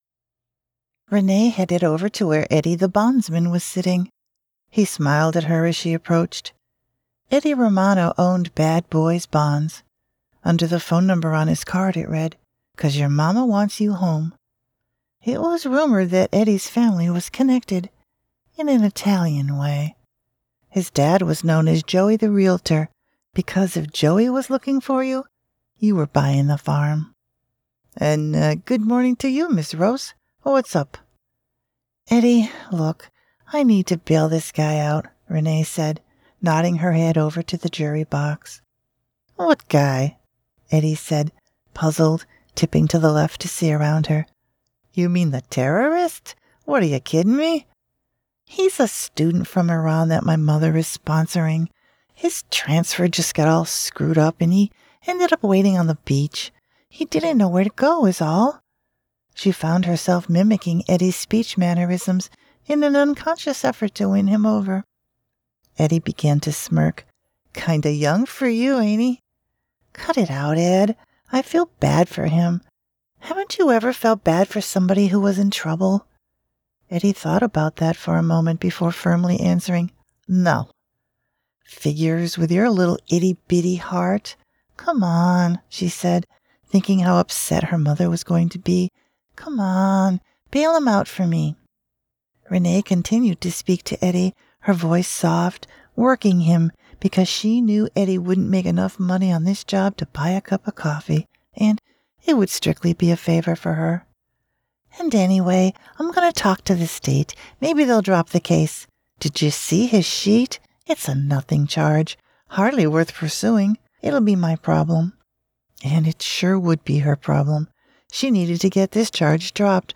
Female
Approachable but never generic — my voice blends friendly warmth with polished clarity.
Audiobooks
Rom Com/ Female And Male Voice
Words that describe my voice are Believable, friendly, approachable.